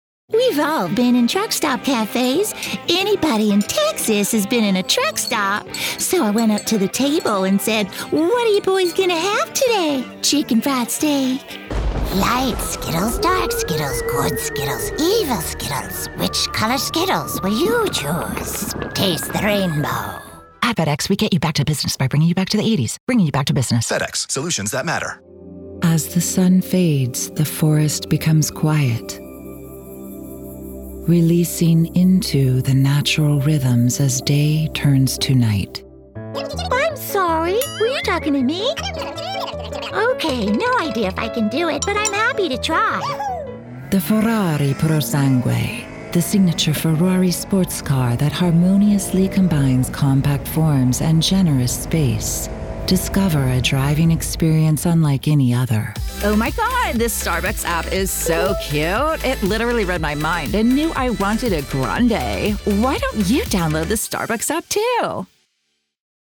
Animation/Character
California/Valley Girl
I am also good at silly/animated voices.
My voice is best for female adult/middle aged/senior. (Age 28+)